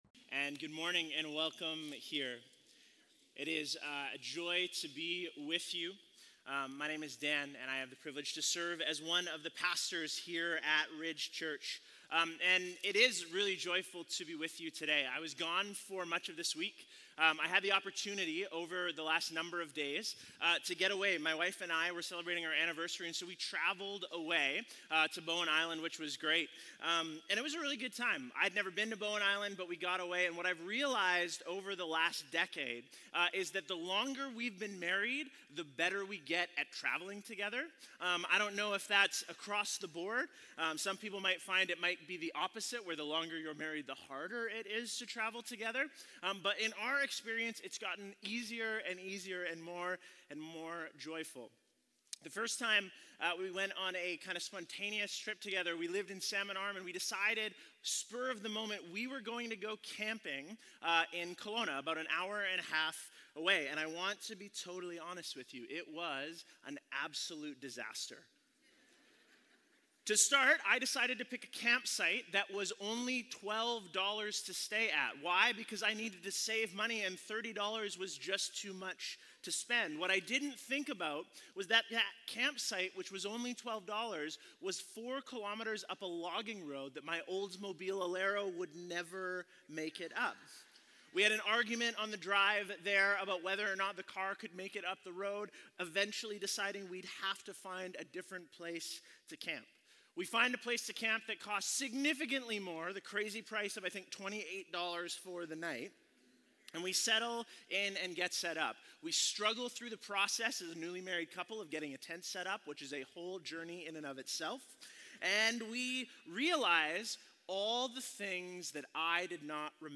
Sermons | Ridge Church